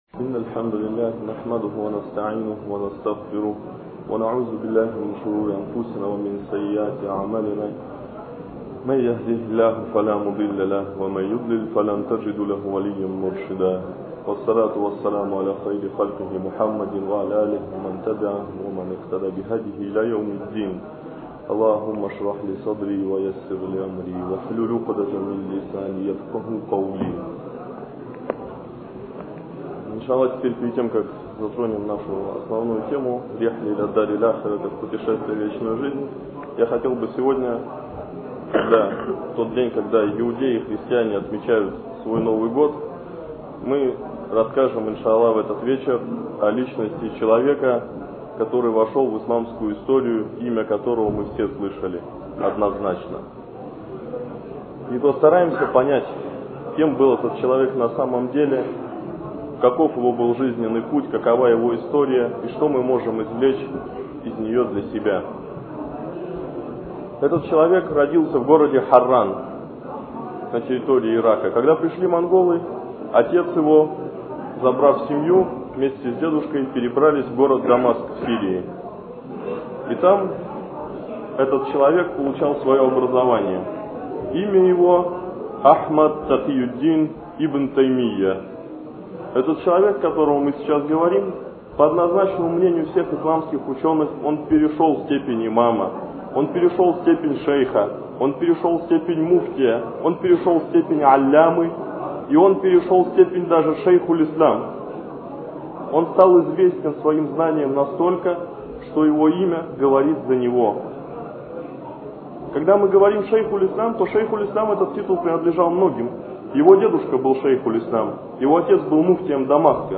Лекции о праведных предках. Эта лекция о величайшем Ученом, Имаме, Шейхе аль-Ислама, Ибн Теймие, да смилуется над ним Аллах.